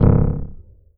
SCIFI_Energy_Pulse_07_mono.wav